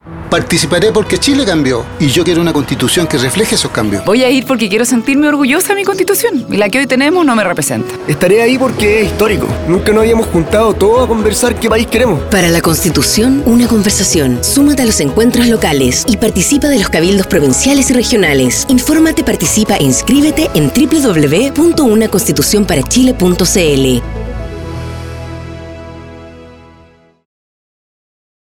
Audio con distintas voces testimoniales que llaman a la participación en el proceso constituyente, especialmente en los encuentros locales, cabildos provinciales y regionales 6.